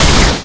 Damage5.ogg